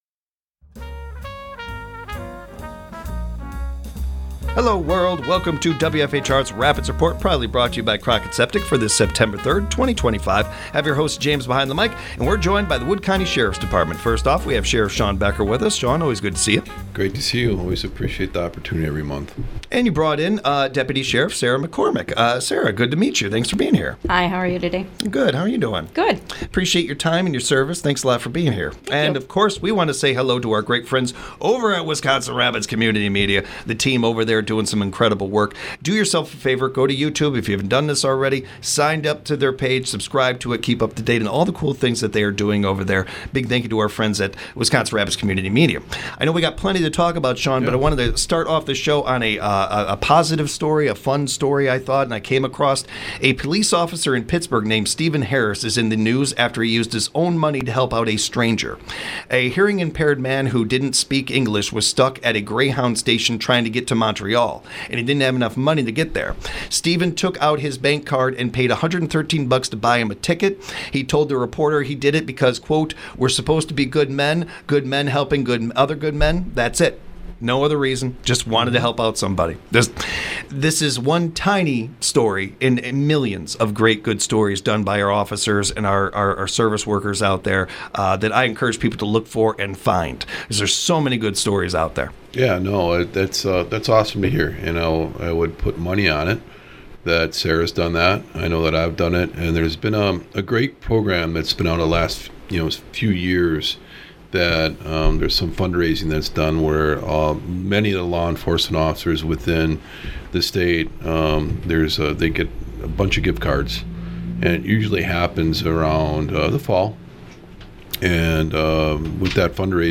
They discuss her career in celebration of her upcoming retirement. This interview was made in collaboration with Wisconsin Rapids Community Media